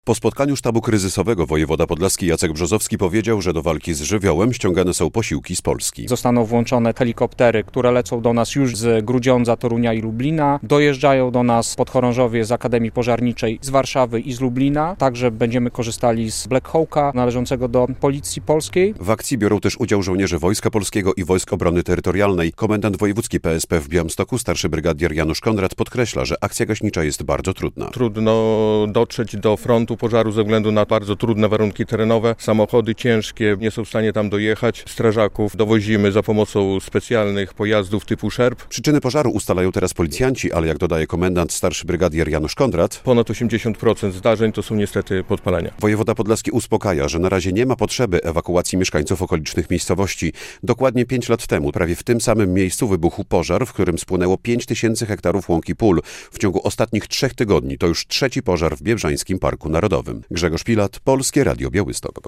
Sytuacja po spotkaniu sztabu kryzysowego dotyczącego pożaru w Biebrzańskim Parku Narodowym - relacja